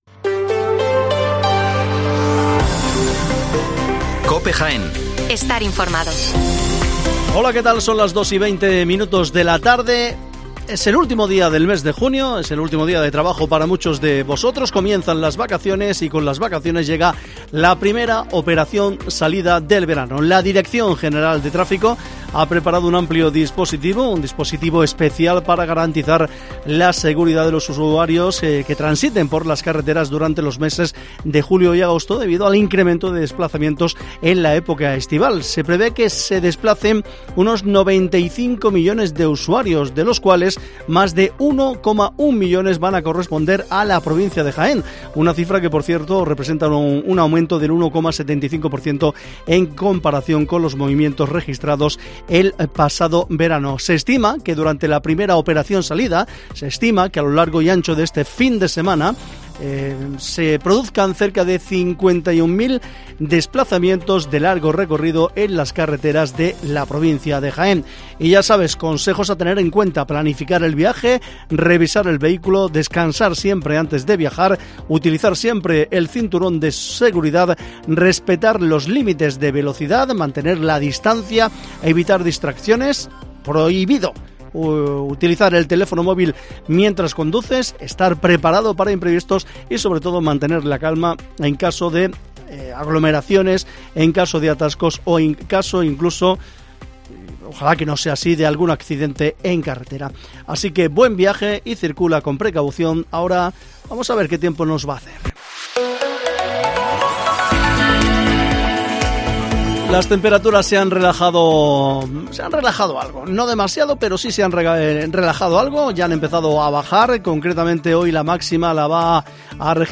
informativo Mediodía Jaén